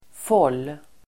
Ladda ner uttalet
Folkets service: fåll fåll substantiv, hem Uttal: [fål:] Böjningar: fållen, fållar Definition: invikt och tillsydd kant på tyg Sammansättningar: kjolfåll (skirt hem) welt substantiv, kantband , fåll , söm , bård